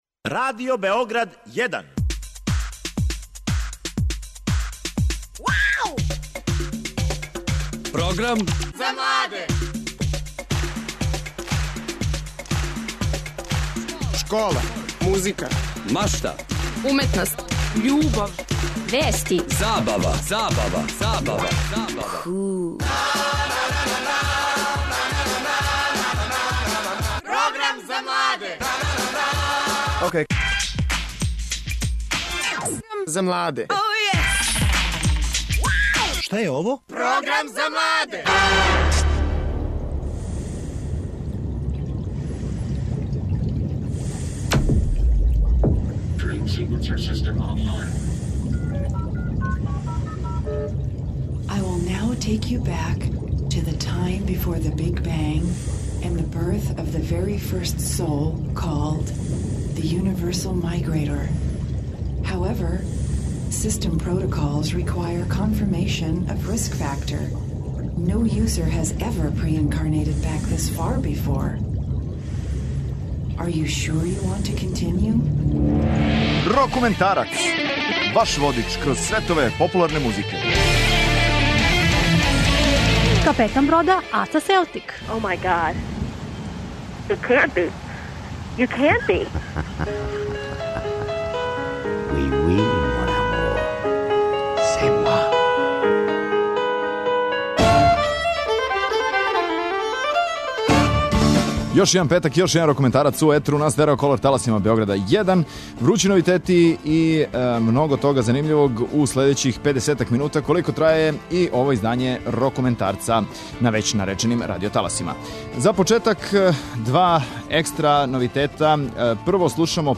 Данас у емисији Рокументарац (шта кажете за превод) слушамо најновије синглове, као и најновије албуме са светске и домаће сцене.